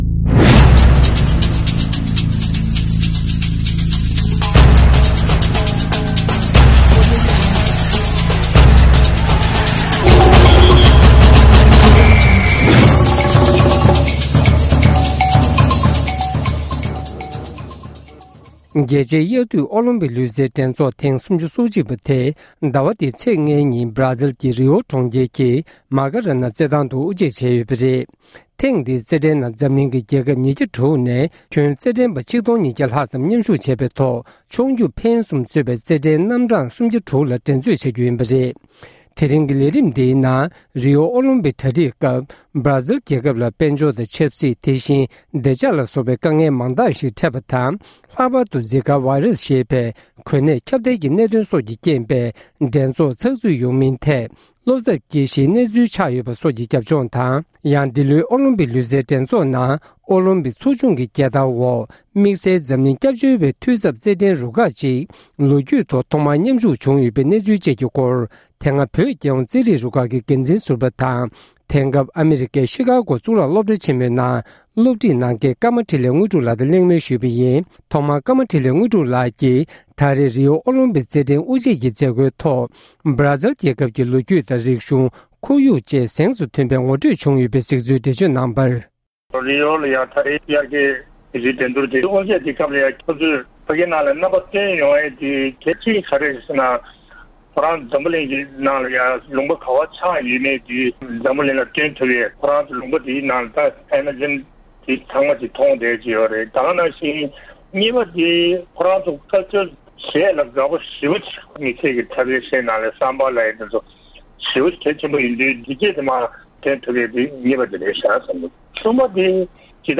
རིའོ་ཨོ་ལིམ་པིག་རྩེད་འགྲན་འགོ་འཛུགས་བྱས་པ། སྒྲ་ལྡན་གསར་འགྱུར།